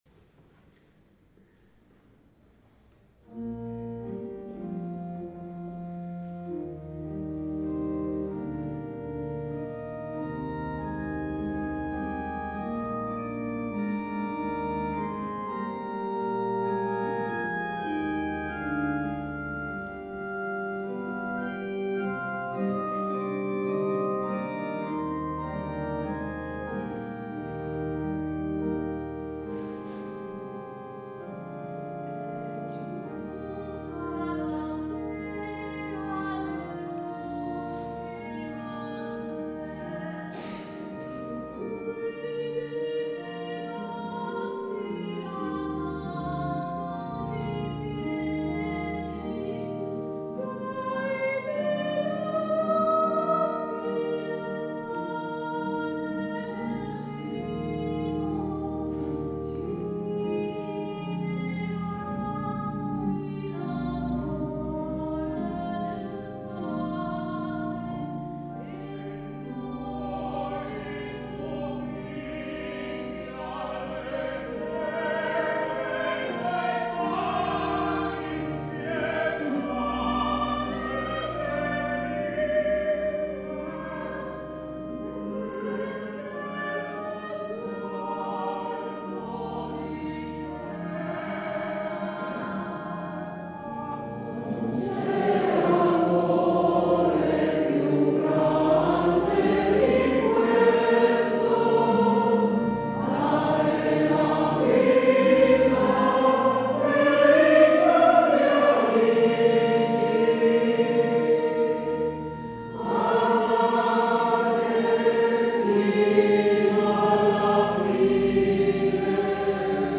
S. Gaudenzio church choir Gambolo' (PV) Italy
3 giugno - Pieve di Sant'Eusebio
- La rassegna dei cori